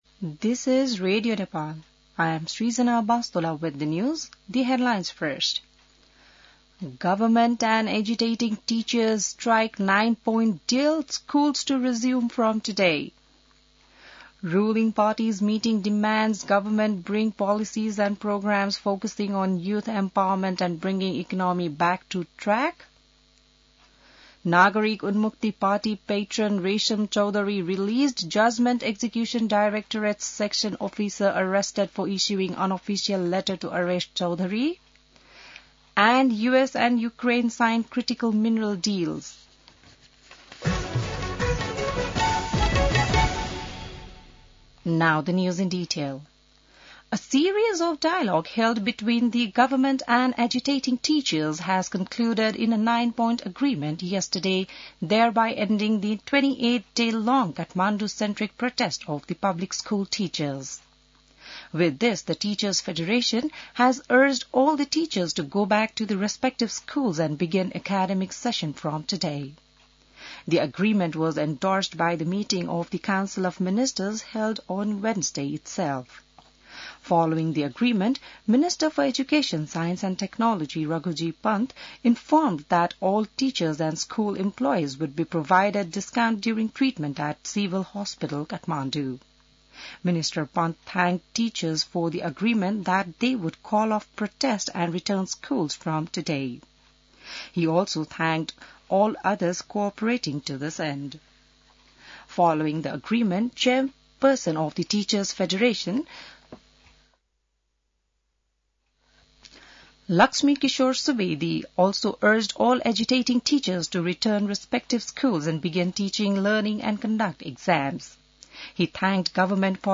बिहान ८ बजेको अङ्ग्रेजी समाचार : १८ वैशाख , २०८२